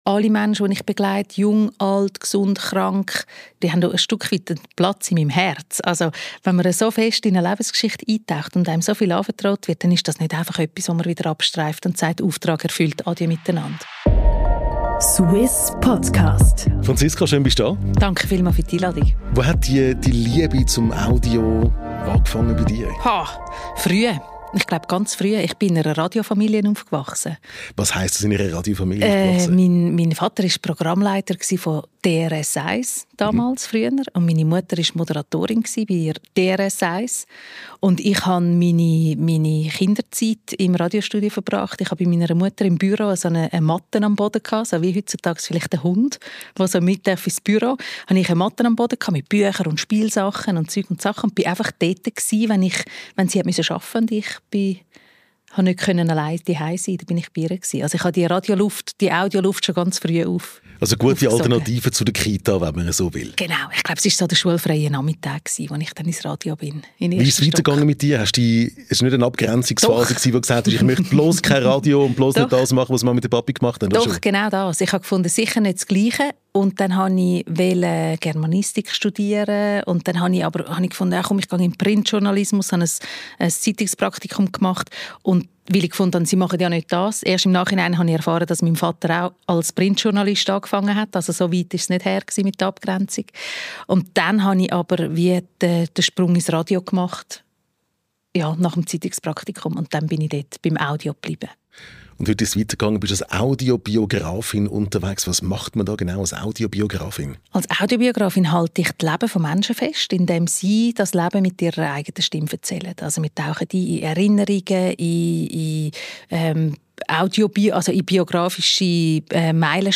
In diesem Podcast-Interview erfahren wir mehr über: